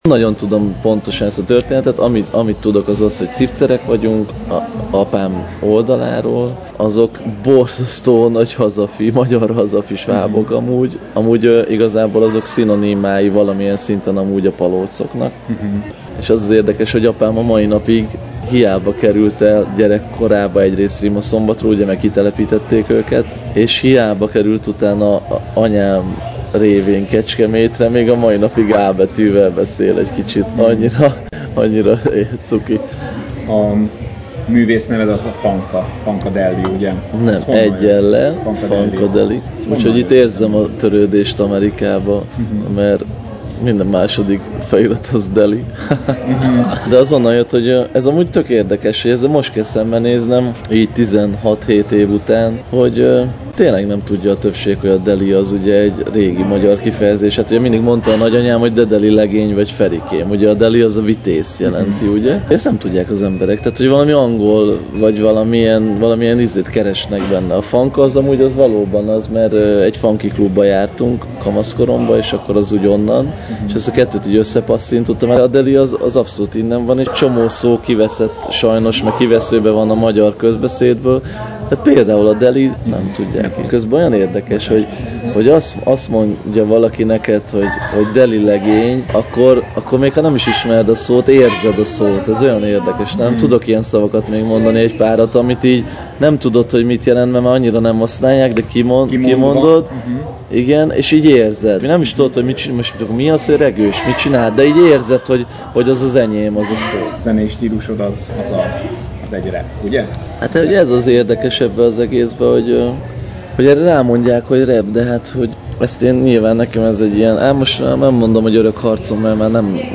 A Lindeni Pálinka Fesztivál fellépője volt Fankadeli, az az Kőházy Ferenc a magyar underground hiphop kiemelkedő képviselője, aki New York-ból érkezett a fesztiválra. Nagyszerű koncertjét élvezhettük az előadónak múlt héten, szombat este. A közönség imádta a számait, amelyeket a fanatikus rajongók kivülről énekeltek.